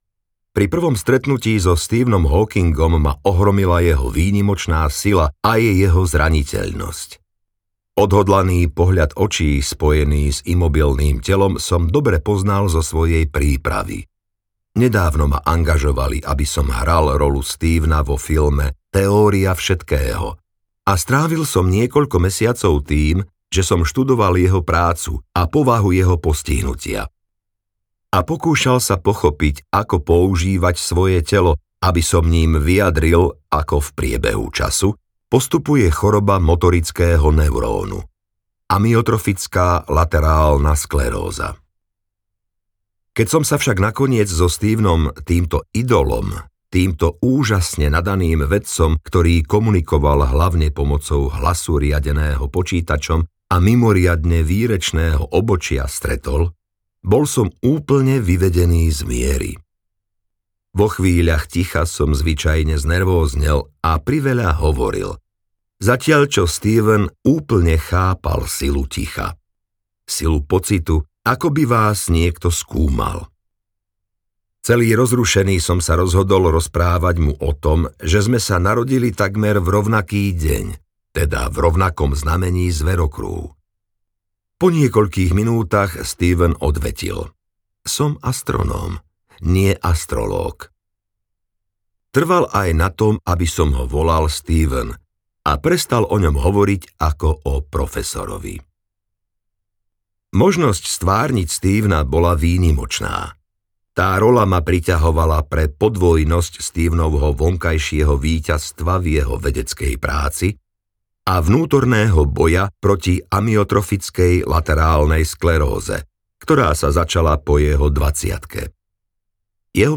Stručné odpovede na veľké otázky audiokniha
Ukázka z knihy